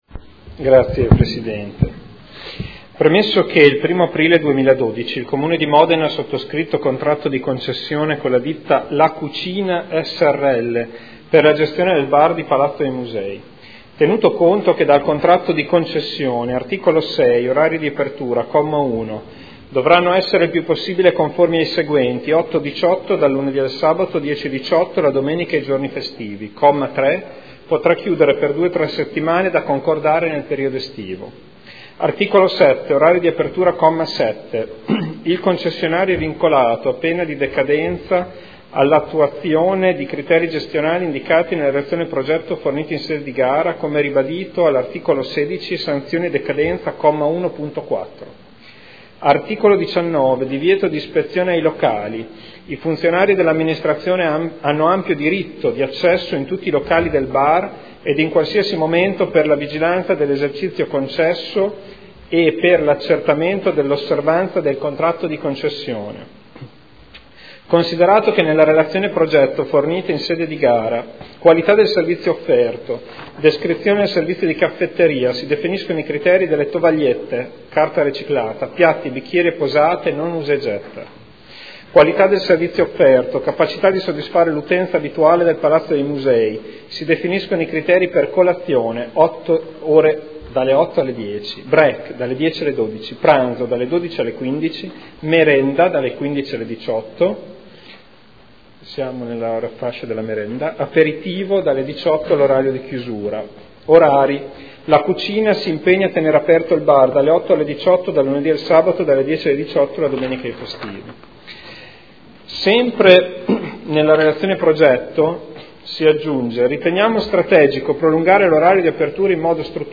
Seduta del 6 marzo. Interrogazione del consigliere Ricci (SEL) avente per oggetto: “Caffetteria dei Musei”